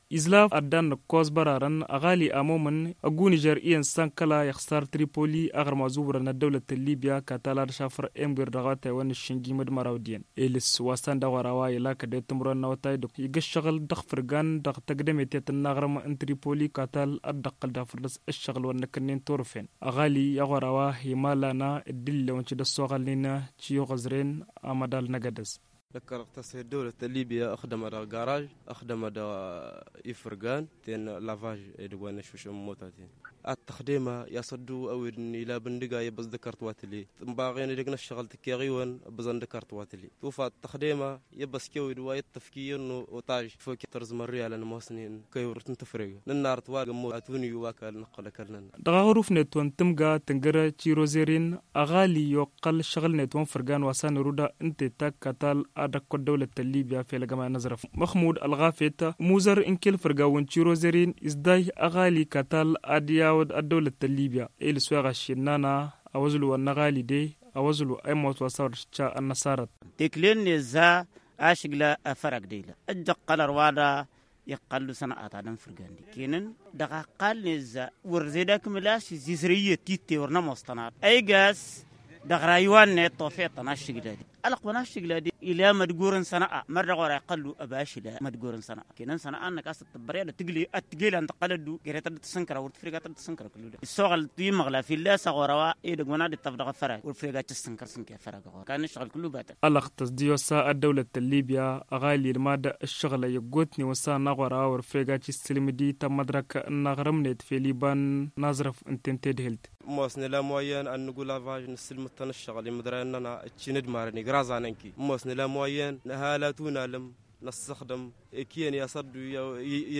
Suivons son portrait dans ce reportage […]